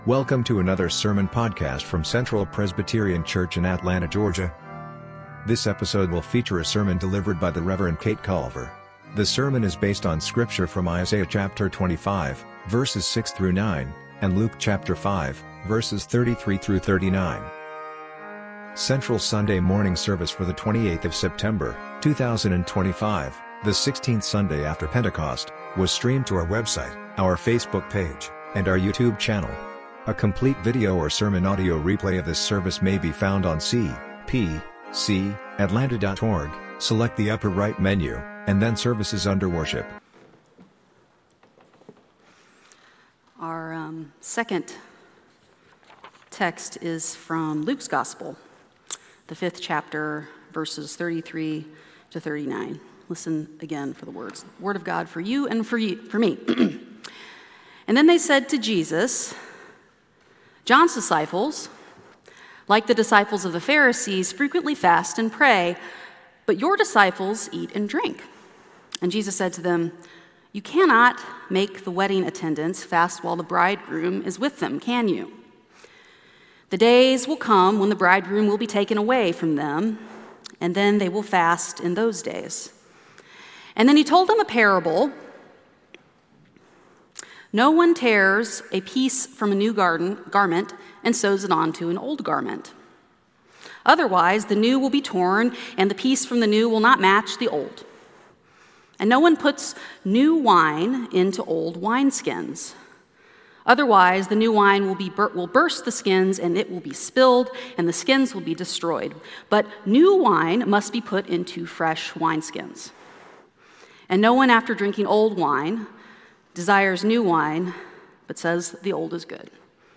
Sermon Audio: